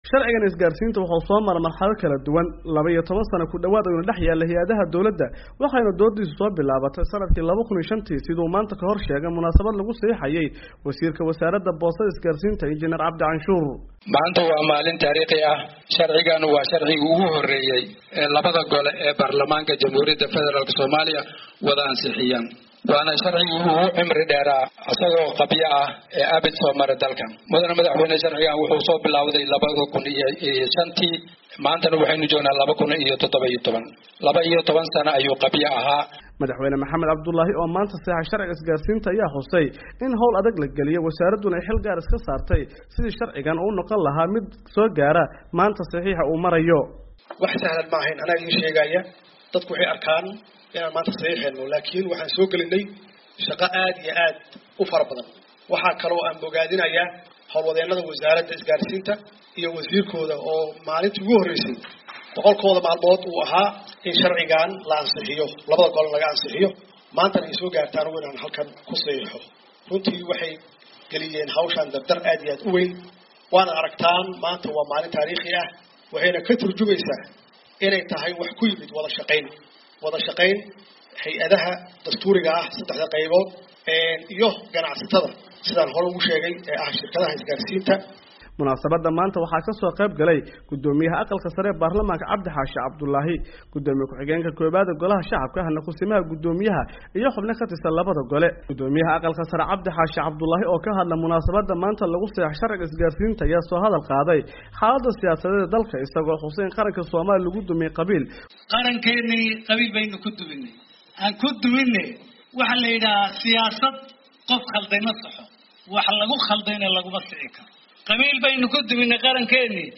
Farmaajo oo ka hadlay munaasabad uu ku saxiixay sharciga isgaarsiinta dalka ayaa sheegay in xukuumadda Ra’iisul Wasaare Xasan Cali Khayre ay mas’uul ka tahay arrimaha siyaasadda, amniga iyo dhaqaalaha.